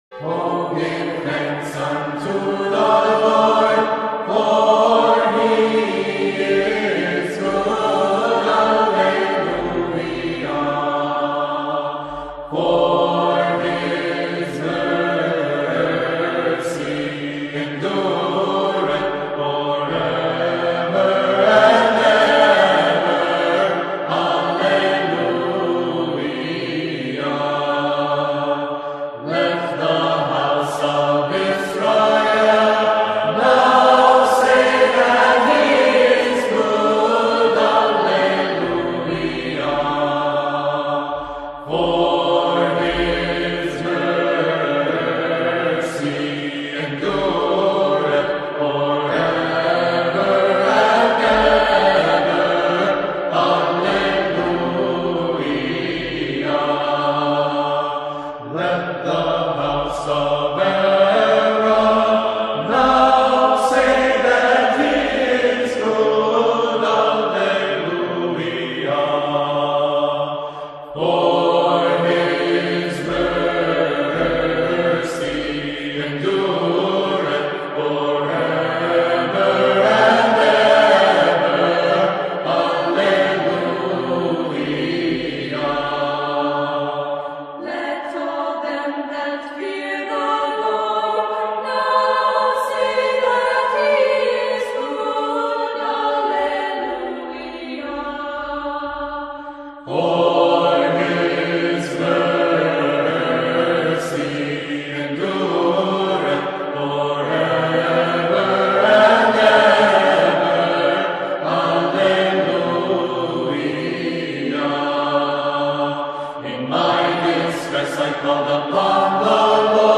O, Give Thanks Unto the Lord 118 (chant)
O-Give-Thanks-Unto-The-Lord-Byzantine-Chant-Psalm-118.mp3